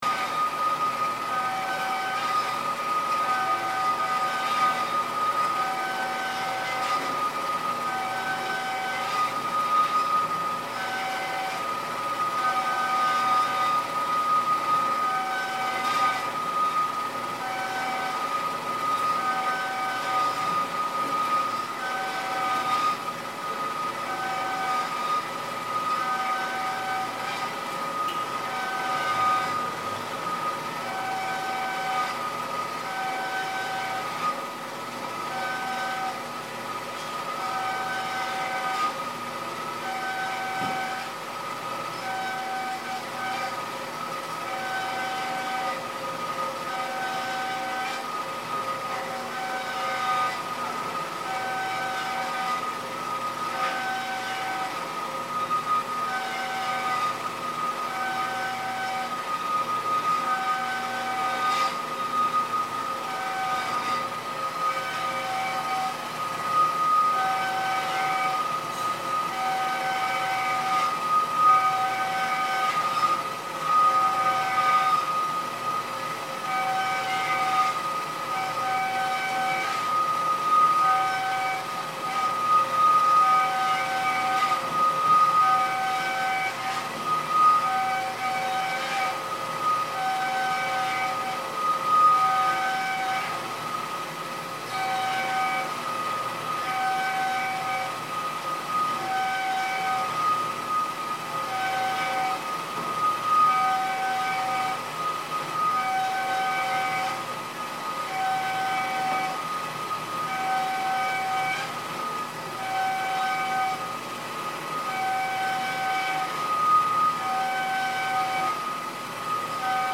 Machine shop in Seoul
Seoul's Mullae-dong is a gentrifying area where small industry sits cheek by jowl with bars and shops. This recording was made from the street into an open fronted metal machine workshop.